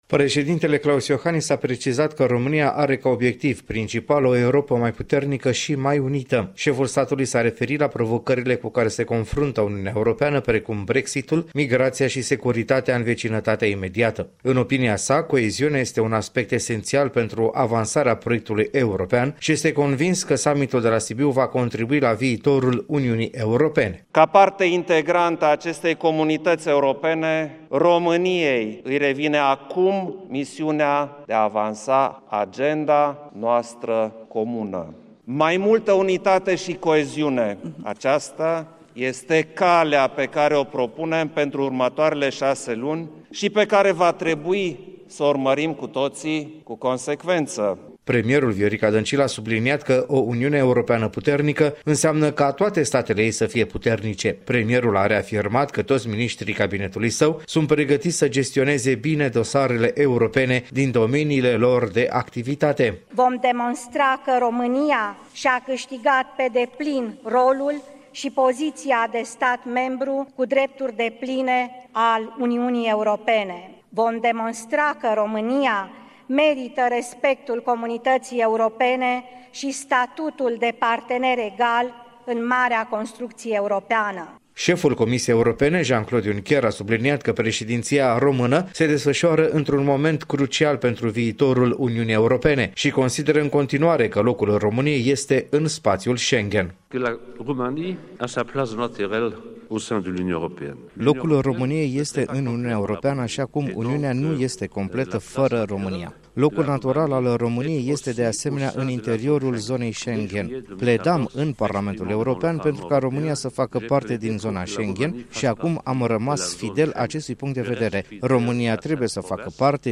Ceremonia a avut loc la Ateneul Român